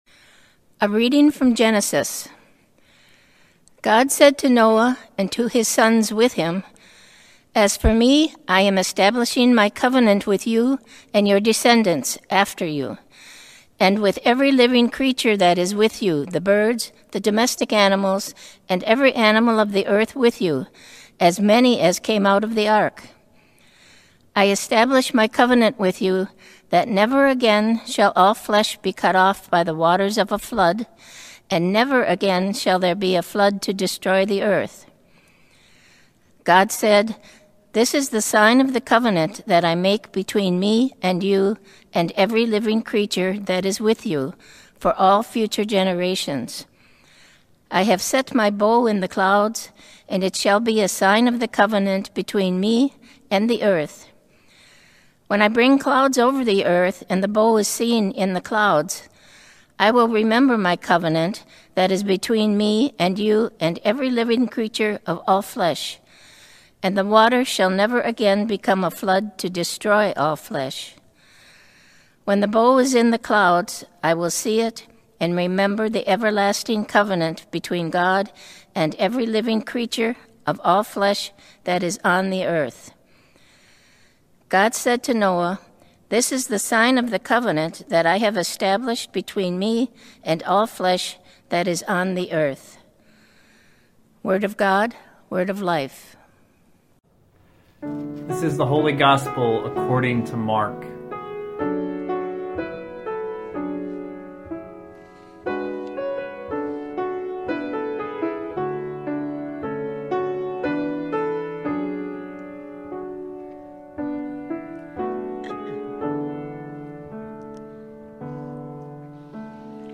Worship Services | Christ The King Lutheran Church